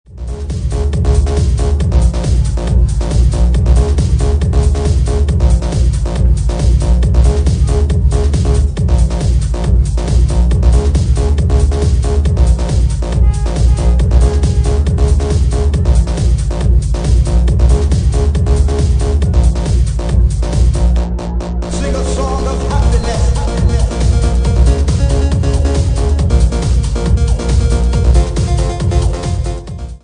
at 138 bpm